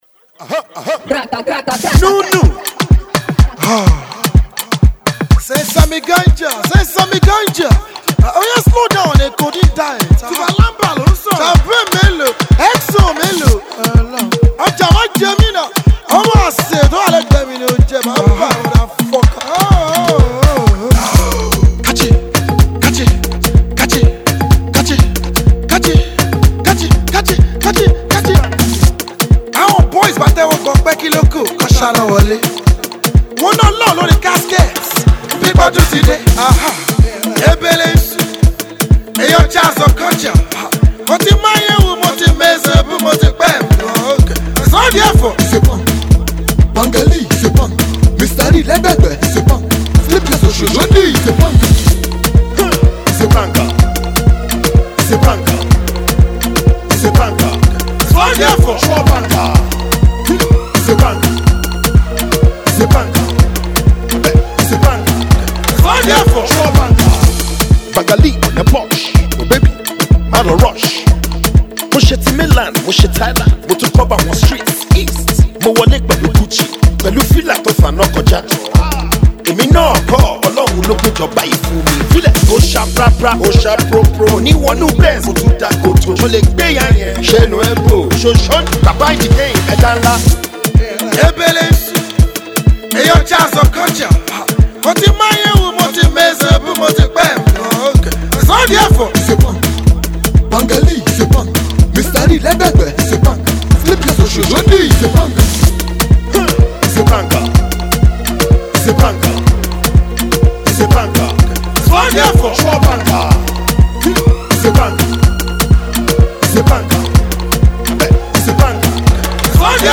street-tailored tune